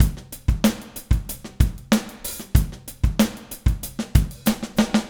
Trem Trance Drums 02 Fill.wav